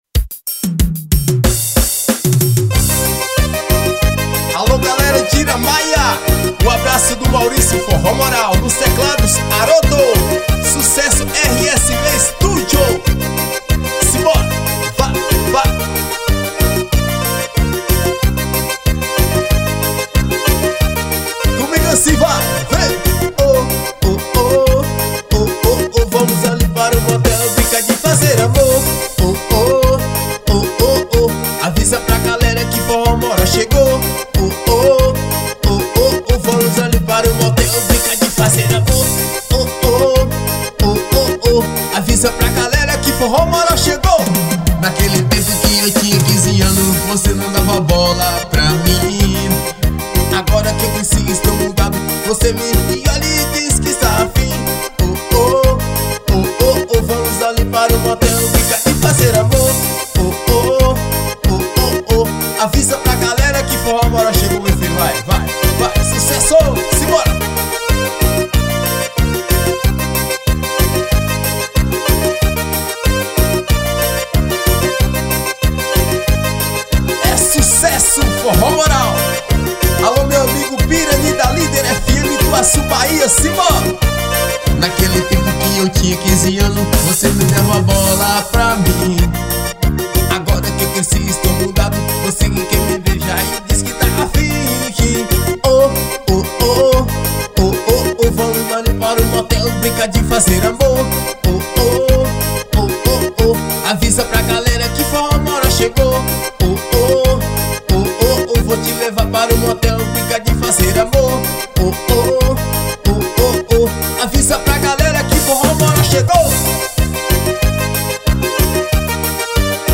nova pancada do forró